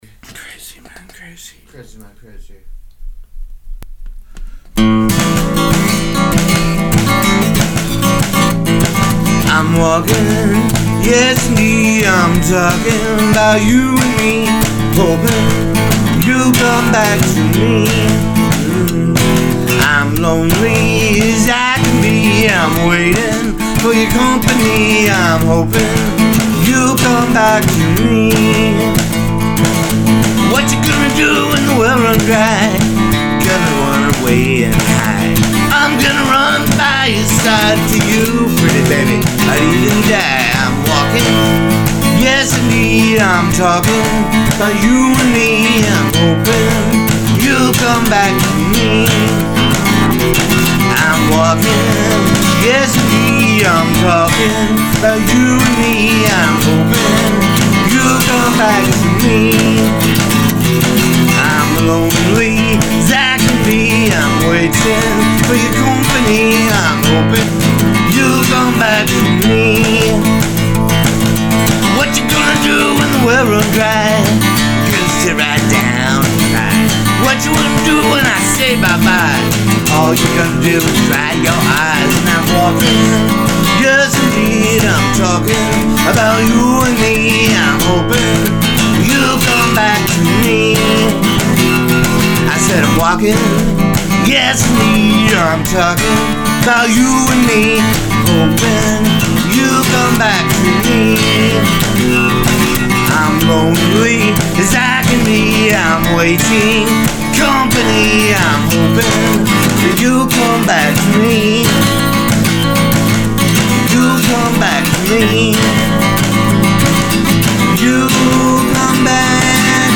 cover…audio file only